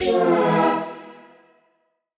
Index of /musicradar/future-rave-samples/Poly Chord Hits/Ramp Down
FR_T-PAD[dwn]-E.wav